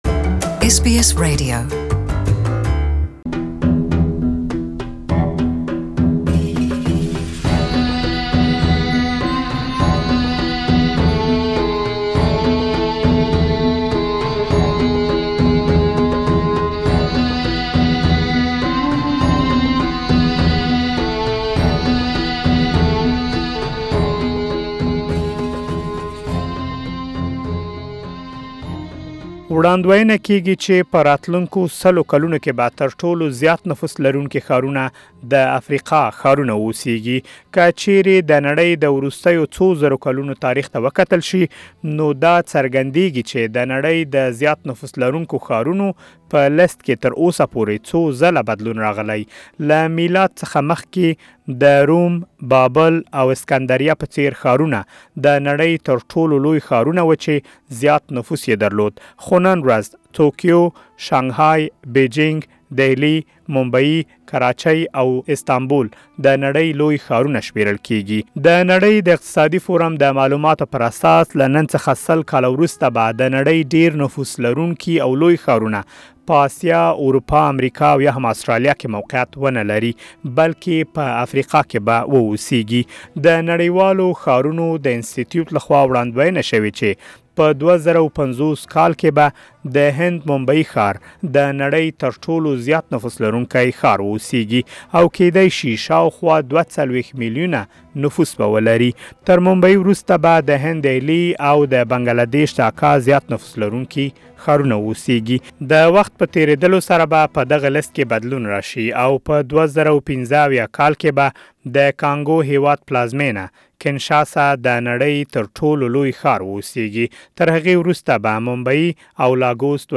نن ورځ د نړۍ تر ټولو ډېر نفوس لرونکي ښارونه په اسیا کې موقعیت لري خو ۱۰۰ کال وروسته به افریقايي ښارونه د نړۍ تر ټولو ډېر نفوس لرونکي ښارونه واوسیږي. مهرباني وکړئ، ، پدې اړه لا ډېر معلومات په راپور کې واورئ.